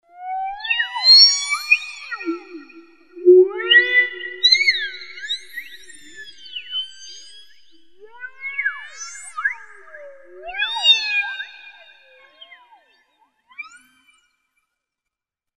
Dolphin.mp3